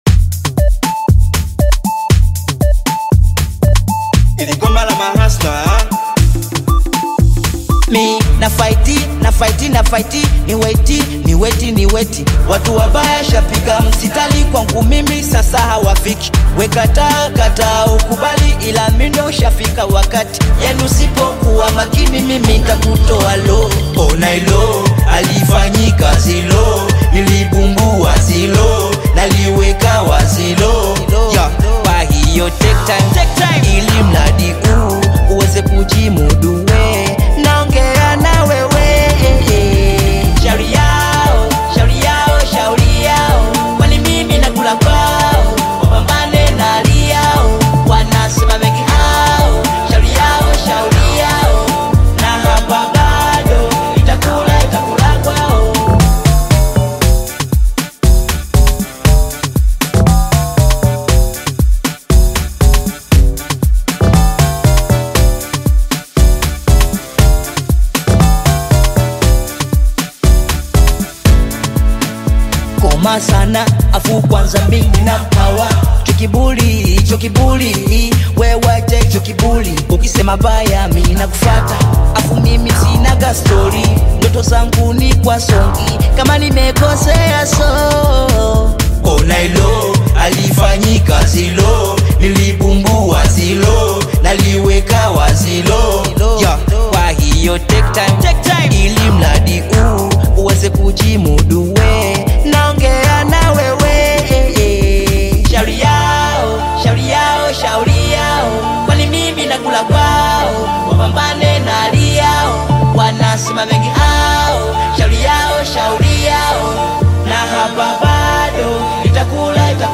Afrobeats/Bongo-Flava single
blending catchy hooks with rhythmic production